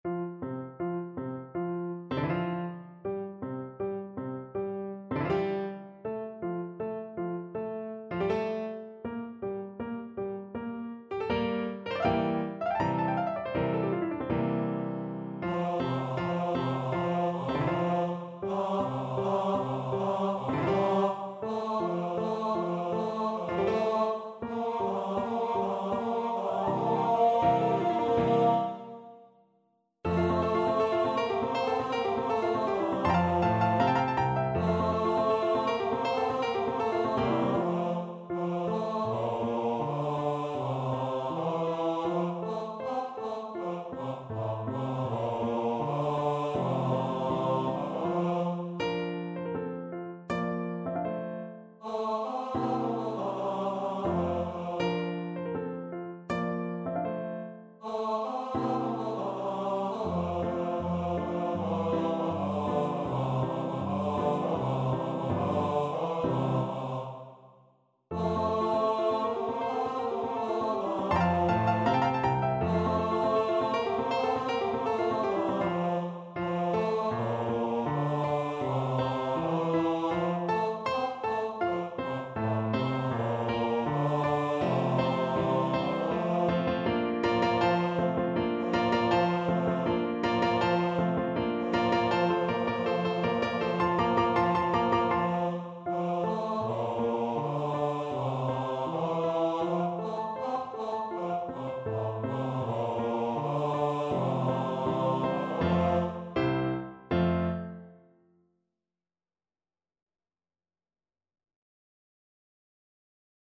Molto allegro =160
4/4 (View more 4/4 Music)
Classical (View more Classical Bass Voice Music)